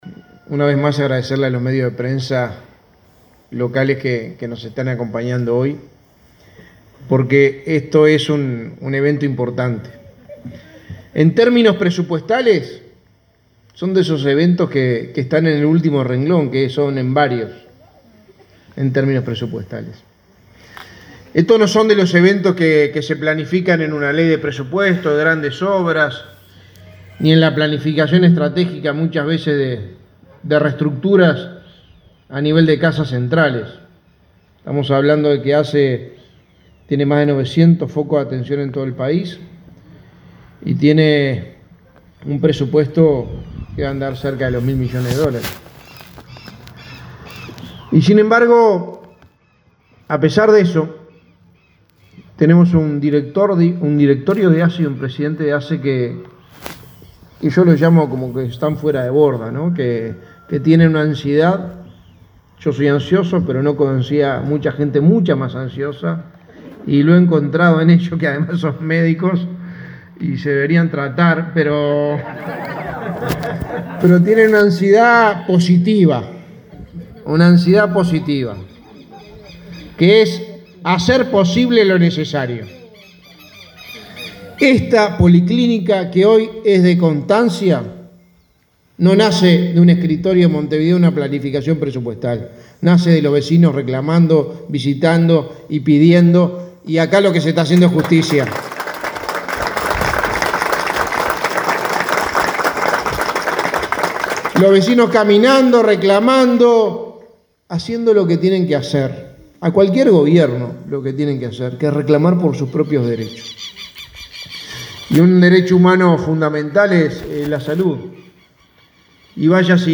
El secretario de Presidencia, Álvaro Delgado, sostuvo este viernes, al participar de la inauguración de una policlínica en la localidad de Constancia,